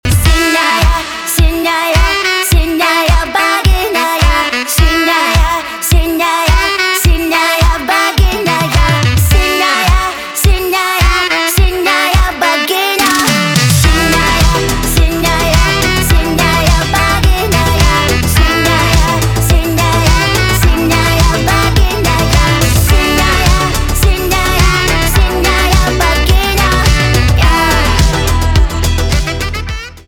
русский рок , гитара , барабаны
труба